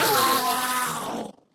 mob / endermen / death.ogg
should be correct audio levels.
death.ogg